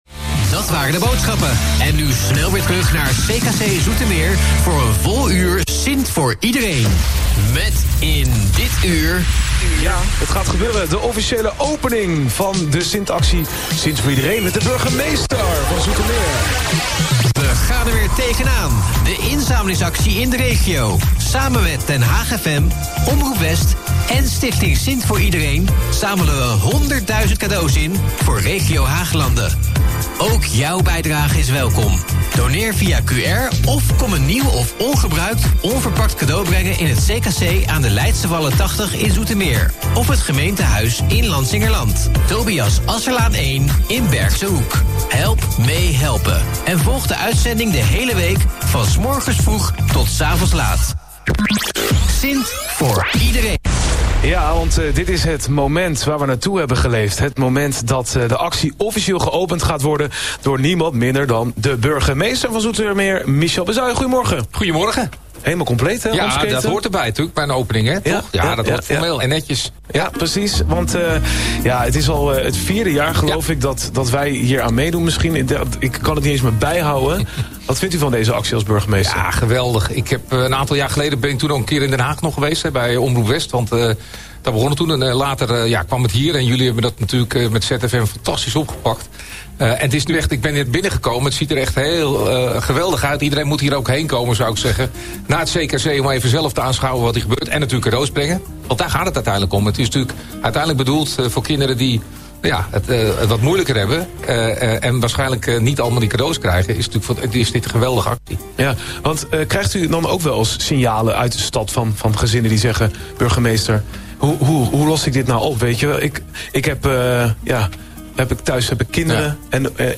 De opening werd verricht door de burgemeester van Zoetermeer, Michel Bezuijen.
Tijdens de live-uitzending riep hij inwoners op om langs te komen en cadeaus te doneren.